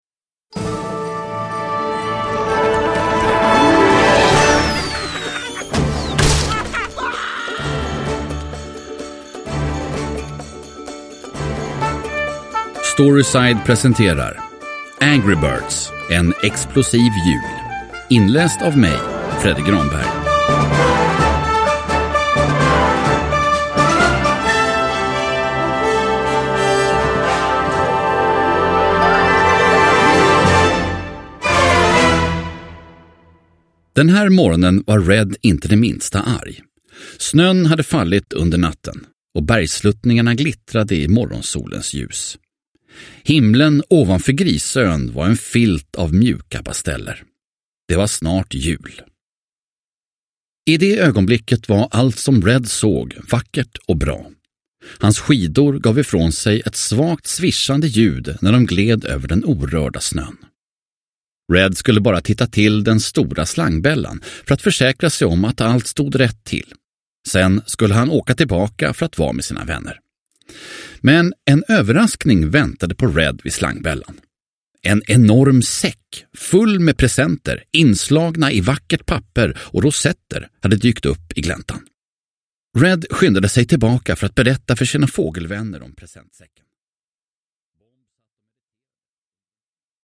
Angry Birds - En explosiv jul – Ljudbok – Laddas ner